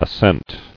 [as·sent]